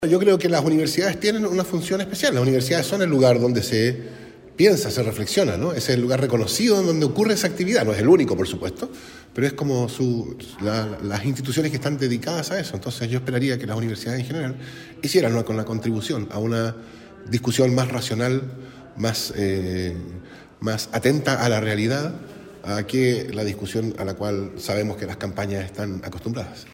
El área Social-Comunitaria del Departamento de Psicología de la Facultad de Ciencias Sociales UdeC organizó el conversatorio «En pos de un voto informado», que contó con la participación de los ex integrantes de la Convención Constitucional, Fernando Atria y Paulina Veloso.